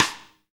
Index of /90_sSampleCDs/Roland L-CD701/KIT_Drum Kits 3/KIT_Pop Kit 1